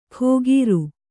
♪ khōgīru